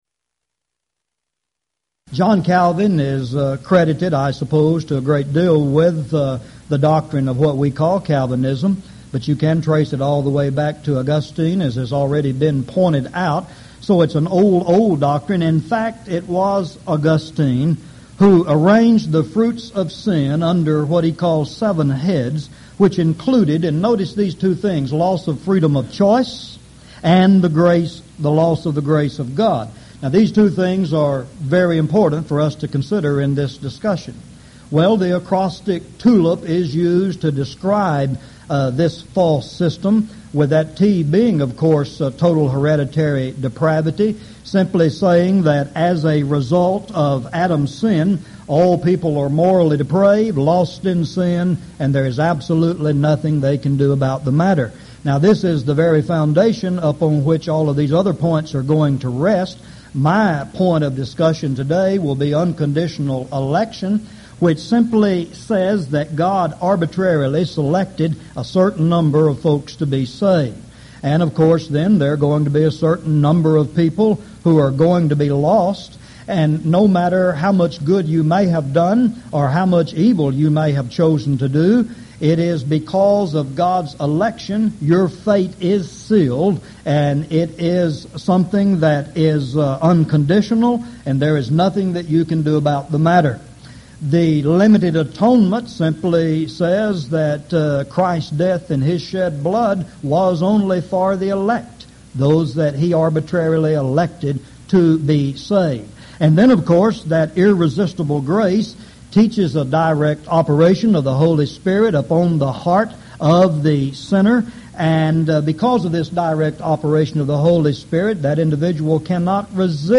Event: 1998 Houston College of the Bible Lectures